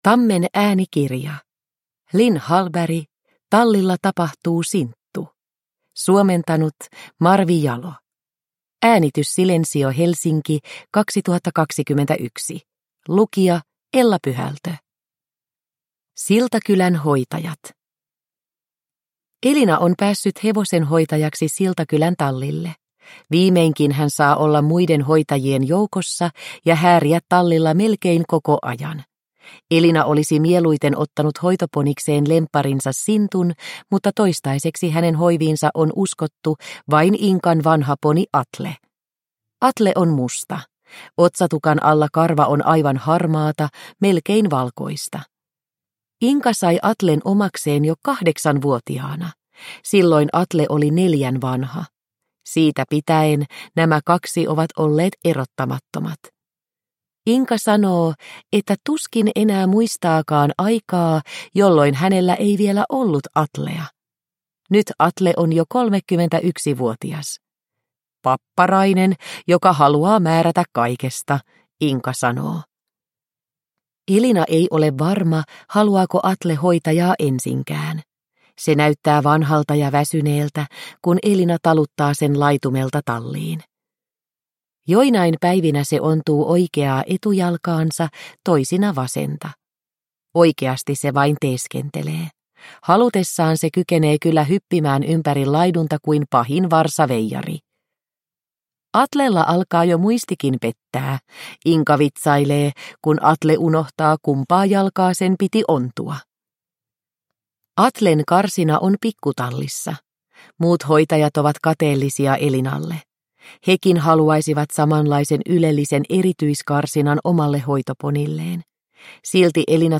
Tallilla tapahtuu, Sinttu – Ljudbok – Laddas ner